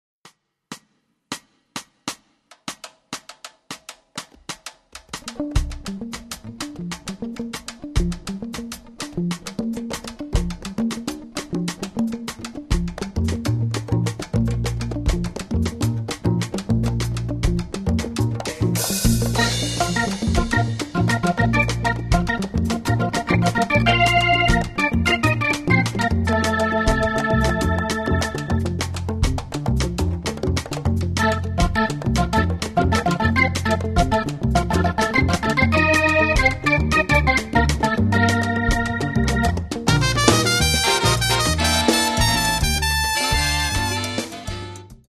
сполучає латиноамериканський та європейський колори джазу
гітара
контрабас
труба
альт саксофон
баритон саксофон